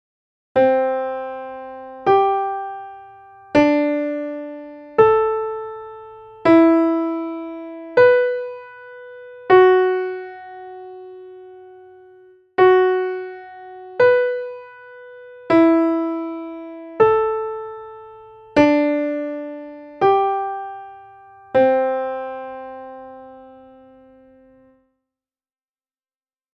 There is a distinctly different outgoing feeling as the scale moves up the ladder of fifths away from the Lydian tonic as opposed to the ingoing feeling as each fifth resolves towards the tonic. Even after we transpose the pitches to remain within the scope of an octave, mixing upwards and downwards movements in pitch, this perception remains:
Lydian Scale by Fifths Transposed Within Octave
Lydian-Scale-by-Fifths-Within-Octave-Outgoing-Ingoing.mp3